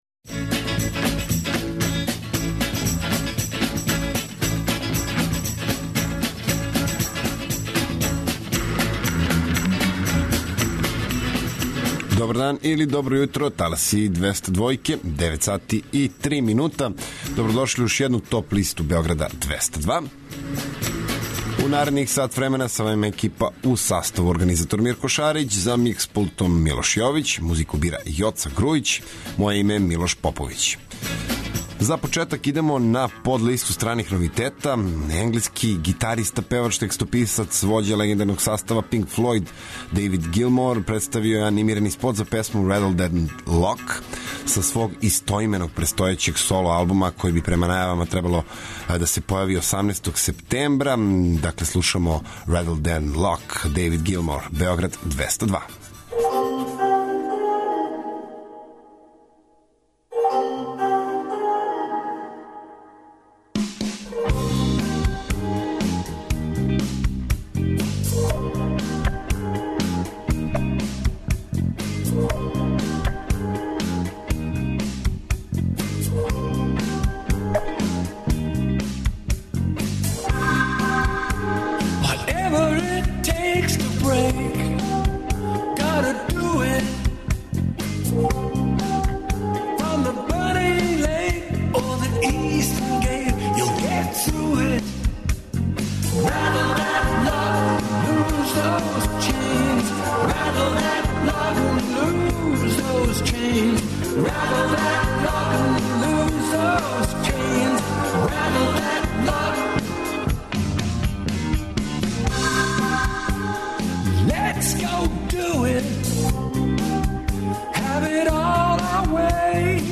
Најавићемо актуелне концерте у овом месецу, подсетићемо се шта се битно десило у историји рок музике у периоду од 17. до 21. августа. Ту су и неизбежне подлисте лектире, обрада, домаћег и страног рока, филмске и инструменталне музике, попа, етно музике, блуза и џеза, као и класичне музике.